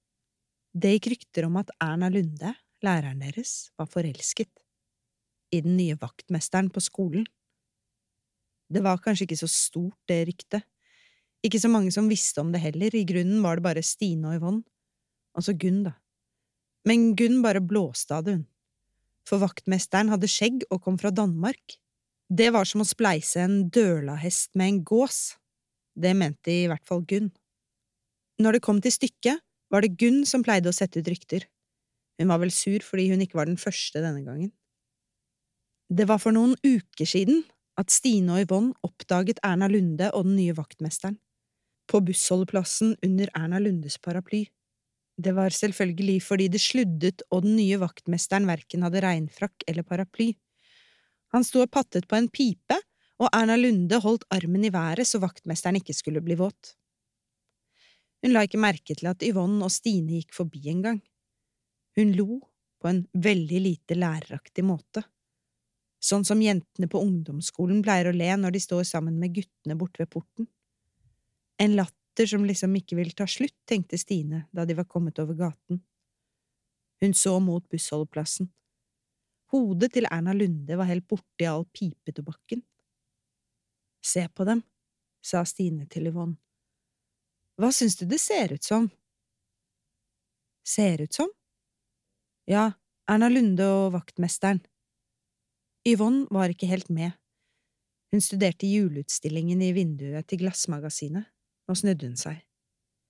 Stine og de bankende hjertene (lydbok) av Tania Kjeldset